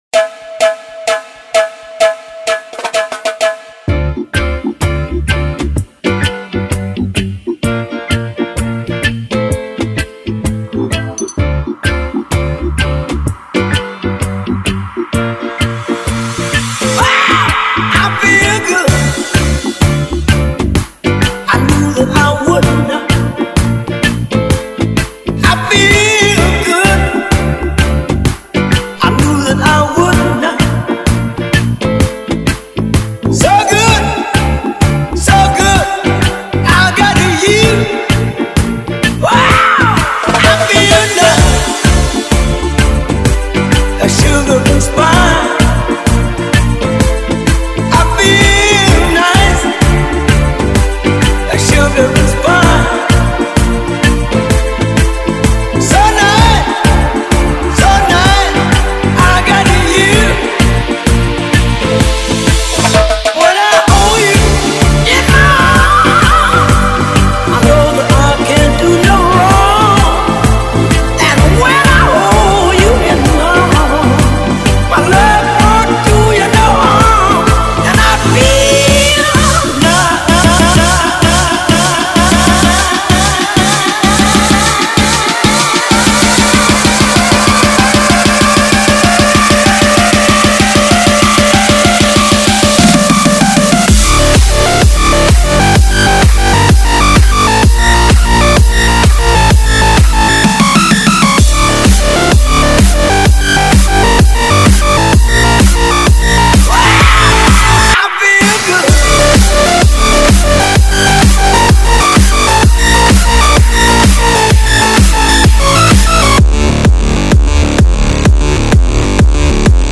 en un mashup al más puro estilo electro house actual